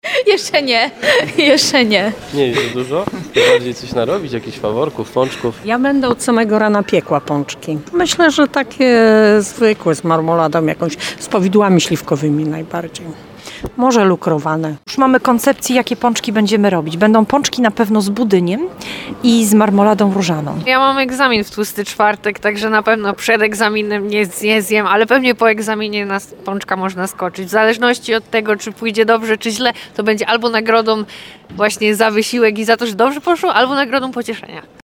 Zapytaliśmy mieszkańców, jakie są ich ulubione pączki oraz ich rekordy.